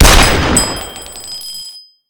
shell.ogg